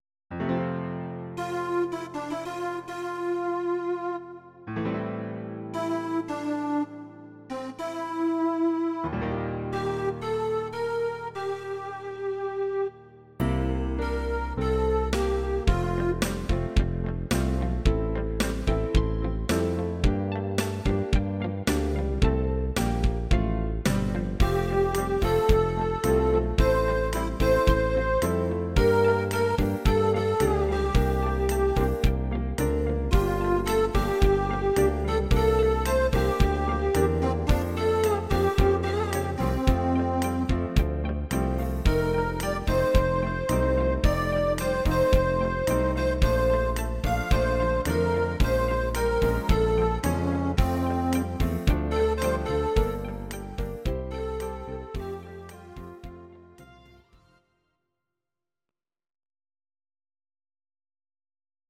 Audio Recordings based on Midi-files
Pop, Oldies, Musical/Film/TV, 1970s